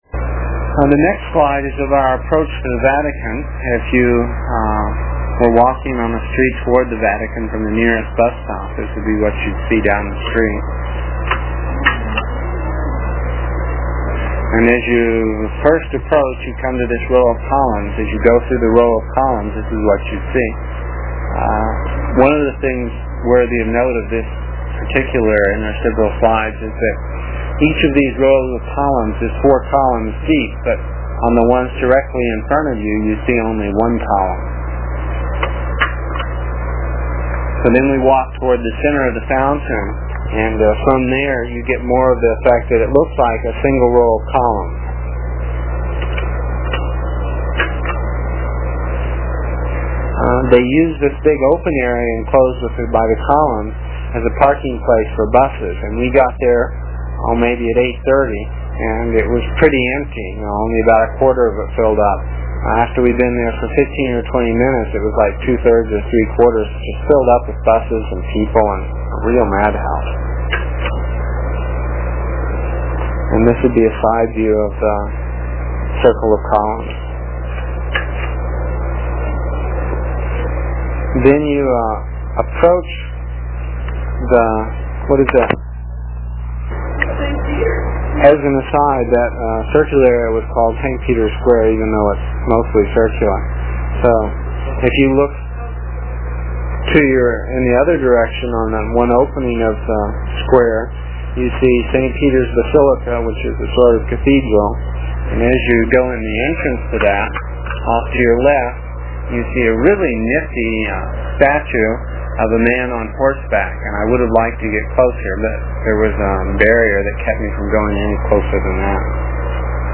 It is from the cassette tapes we made almost thirty years ago. I was pretty long winded (no rehearsals or editting and tapes were cheap) and the section for this page is about four minutes and will take about a minutes to download with a dial up connection.